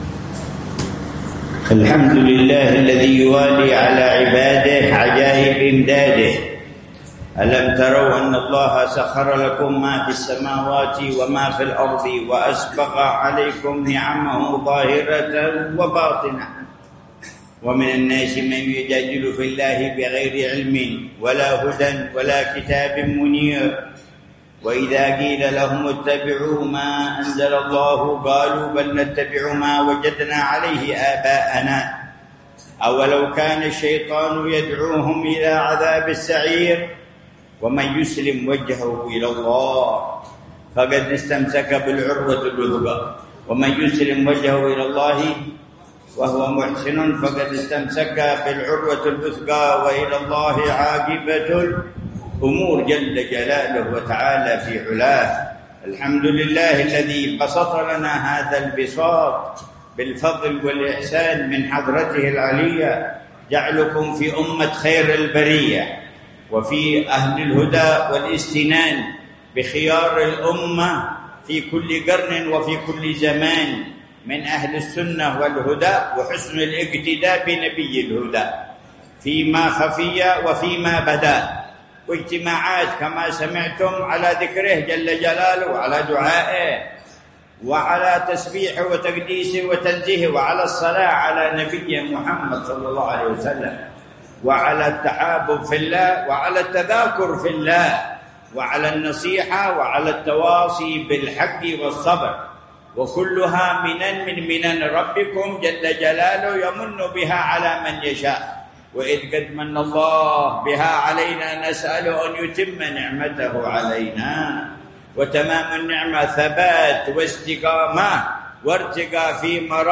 محاضرة العلامة الحبيب عمر بن محمد بن حفيظ في مسجد الحبيب عبد القادر بن أحمد السقاف، بعيديد، مدينة تريم، ليلة الثلاثاء 8 ربيع الثاني 1447هـ بعنوان: